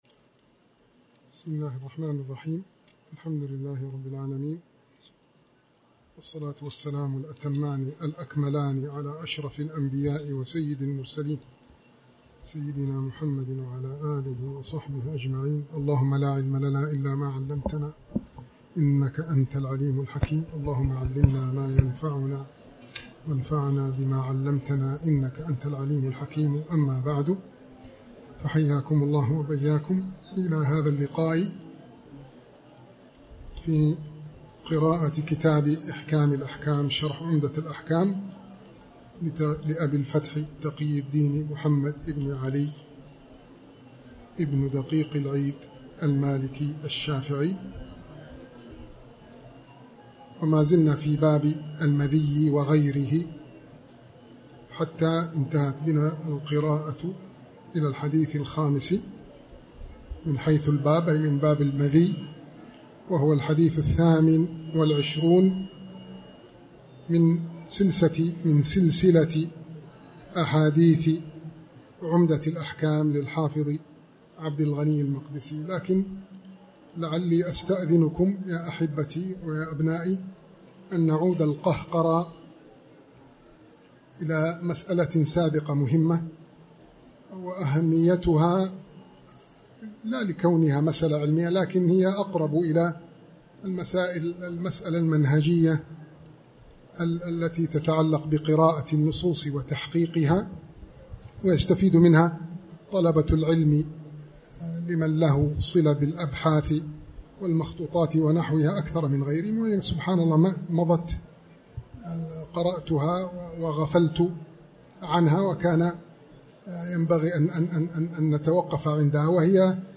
تاريخ النشر ١٥ صفر ١٤٤٠ هـ المكان: المسجد الحرام الشيخ